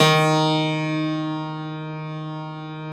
53e-pno07-D1.wav